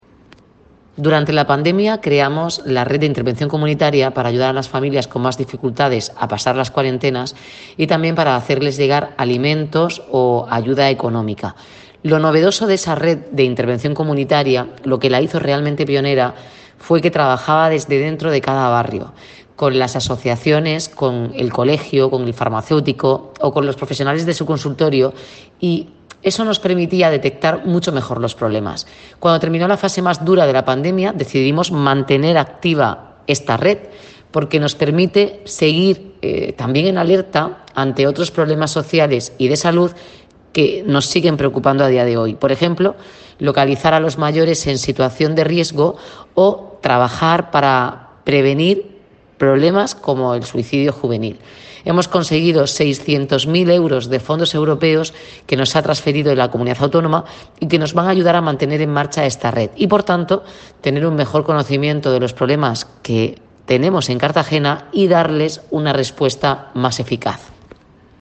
Declaraciones de la alcaldesa y concejal de Bienestar Social, Noelia Arroyo